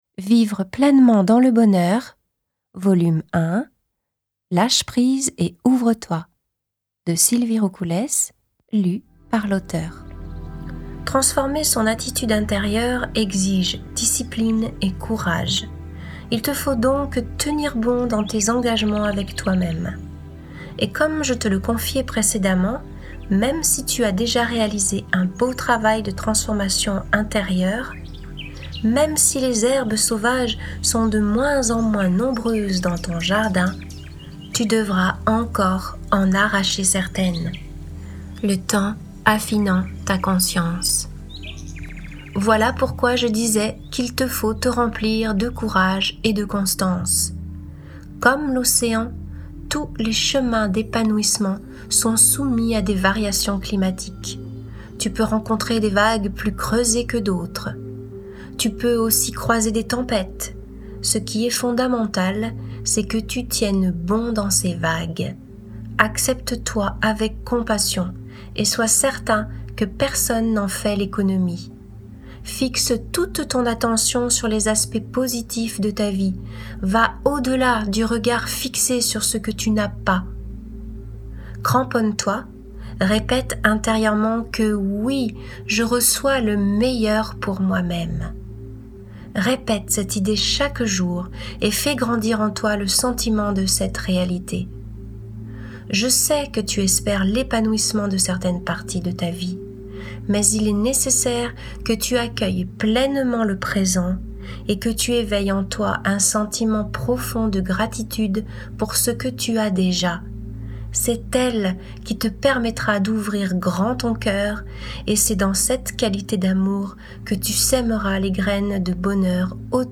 Get £2.33 by recommending this book 🛈 Comment construire pas à pas un état de bonheur ? La voix douce